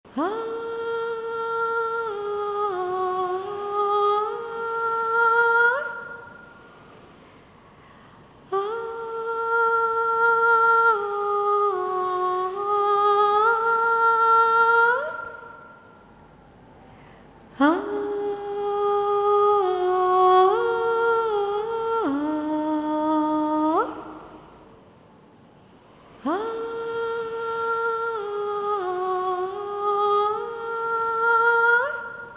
детская музыка
саундтрек , спокойные
грустные , колыбельные
без слов